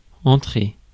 Ääntäminen
IPA: [ɑ̃.tʁe]